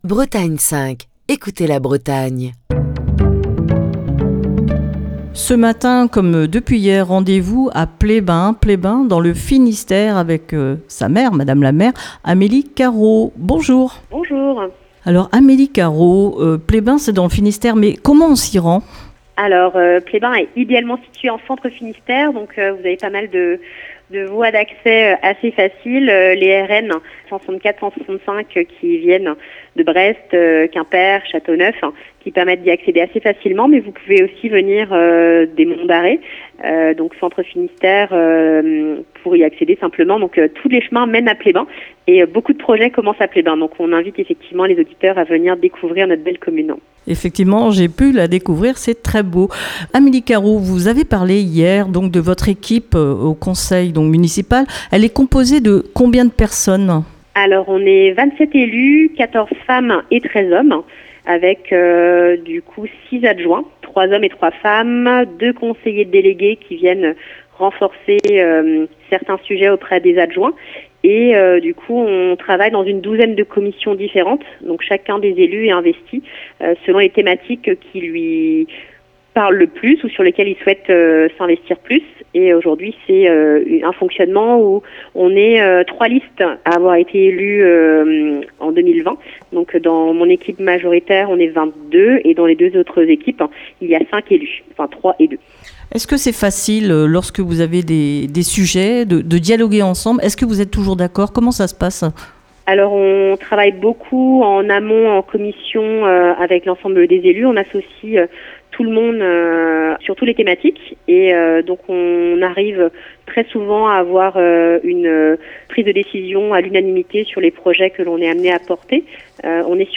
Cette semaine, Destination commune pose ses micros dans le Finistère à Pleyben.